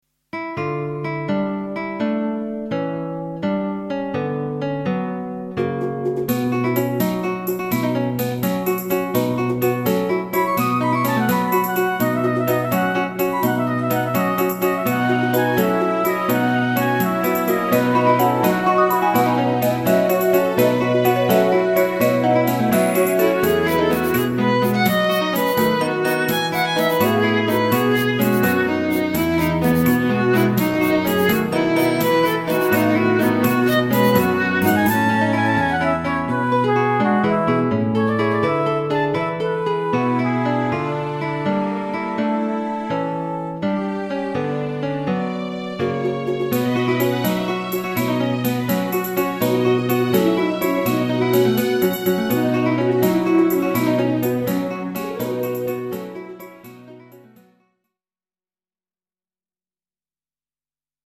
klassiek
Orkestraal en modern, doch met een zweem oude volksmuziek.